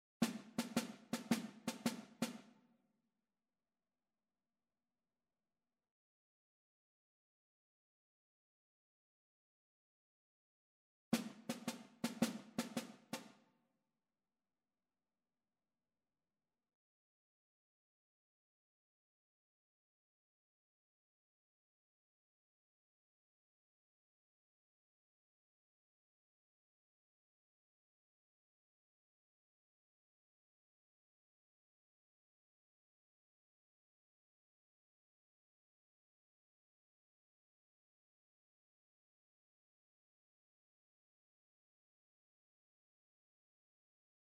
Schumann: Śmiały jeździec (na flet i fortepian)
Symulacja akompaniamentu